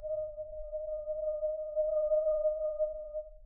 Percussion
alien1_pp.wav